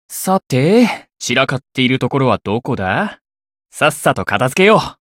觉醒语音 好了，是哪里东西没整理好？